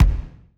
EXPLDsgn_Explosion Impact_06_SFRMS_SCIWPNS.wav